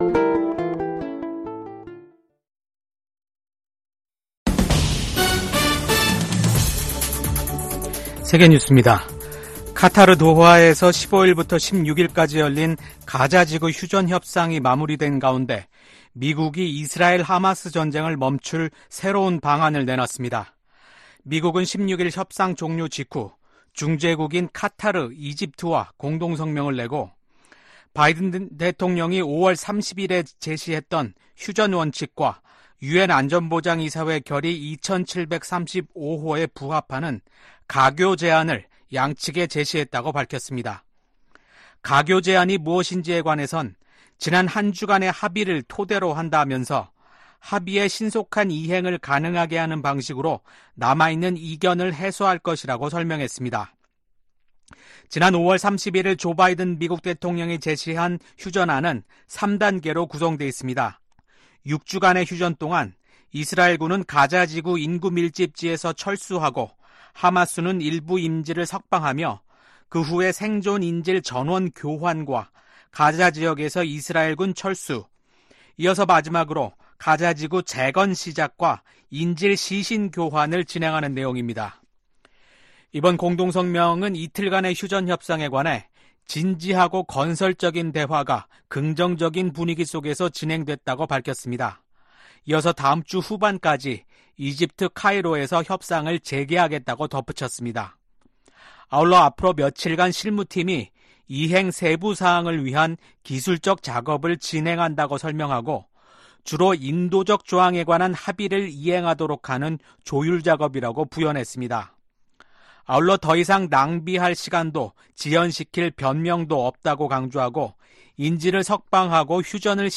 VOA 한국어 아침 뉴스 프로그램 '워싱턴 뉴스 광장' 2024년 8월 17일 방송입니다. 미국 정부가 일본 고위 당국자들의 야스쿠니 신사 참배를 “과거 지향적”이라고 평가했습니다. 백악관 당국자가 연내 미한일 3국 정상회담 개최 가능성을 거론했습니다. 윤석열 한국 대통령이 발표한 자유에 기반한 남북 통일 구상과 전략에 대해 미국 전문가들은 “미래 비전 제시”라고 평가했습니다.